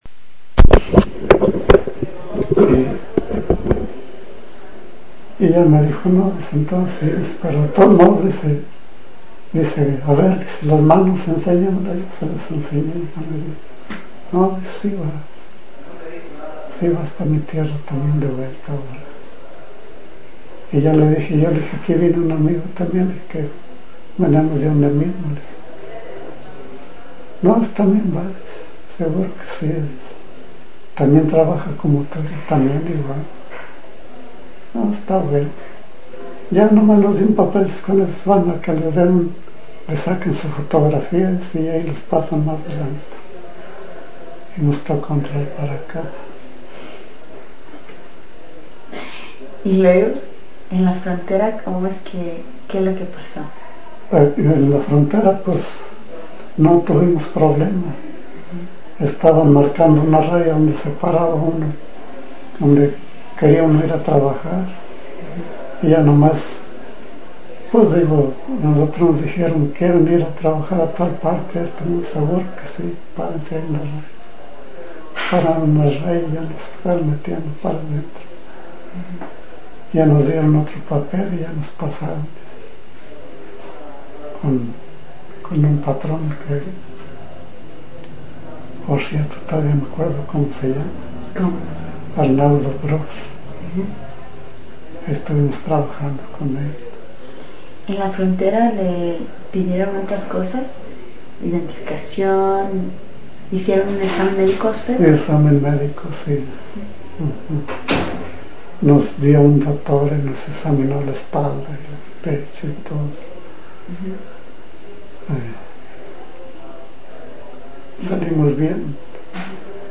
Summary of Interview